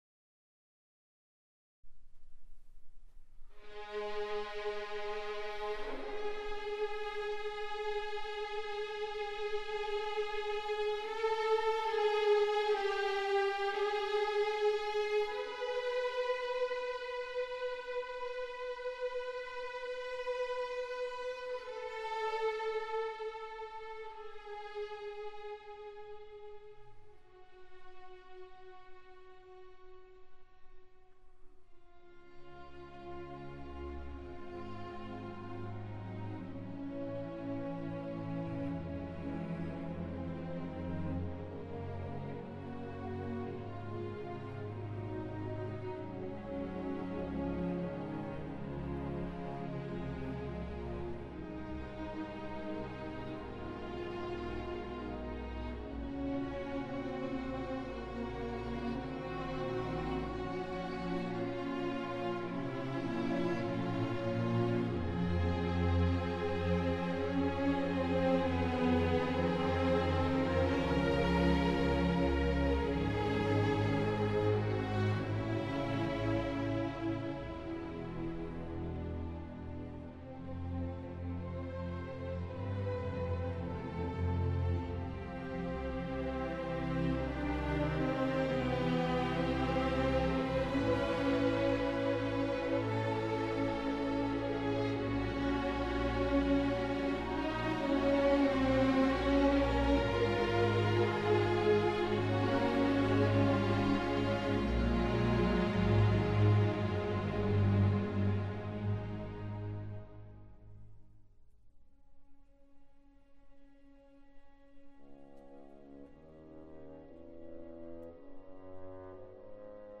2nd violin